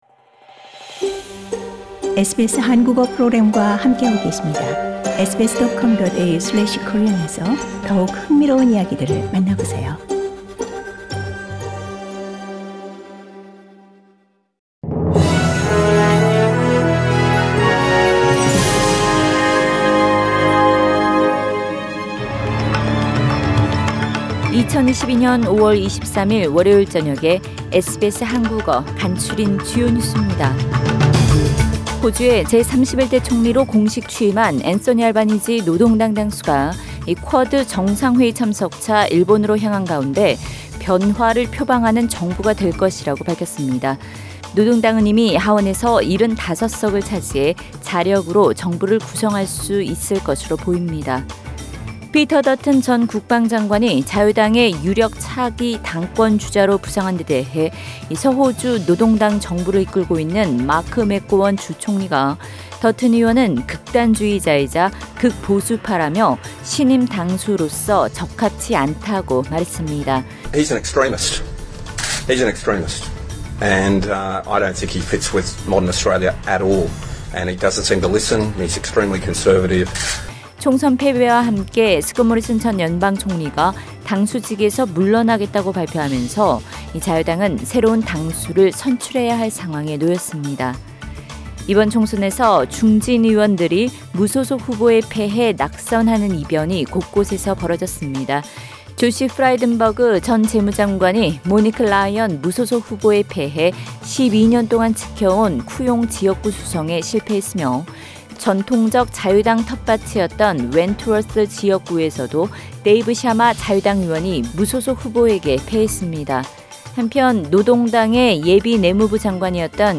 2022년 5월 23일 월요일 저녁 SBS 한국어 간추린 주요 뉴스입니다.